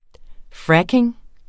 Udtale [ ˈfɹakeŋ ]